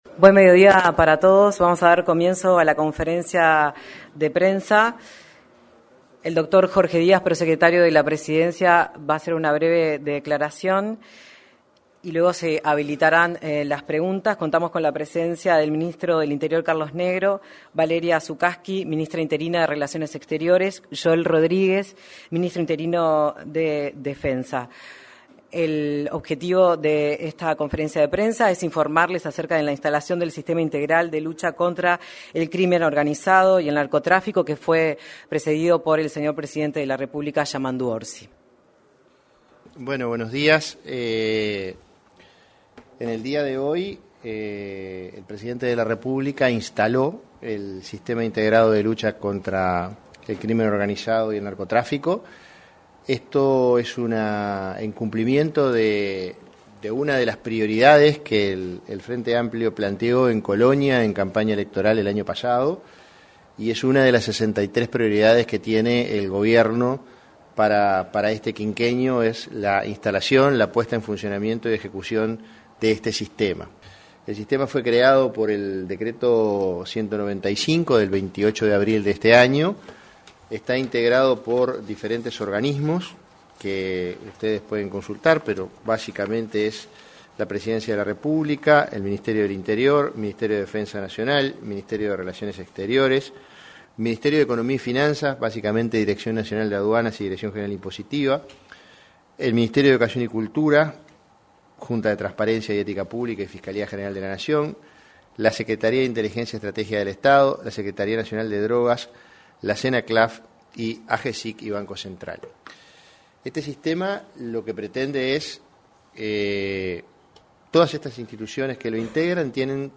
Conferencia de prensa del prosecretario de Presidencia de la República
Conferencia de prensa del prosecretario de Presidencia de la República 13/05/2025 Compartir Facebook Twitter Copiar enlace WhatsApp LinkedIn Este martes 13, el prosecretario de la Presidencia de la República, Jorge Díaz; el ministro del Interior, Carlos Negro, y su par interina de Relaciones Exteriores, Valeria Csukasi, efectuaron declaraciones en una conferencia de prensa, luego de la primera reunión del Sistema Integrado de Lucha contra el Crimen Organizado y el Narcotráfico.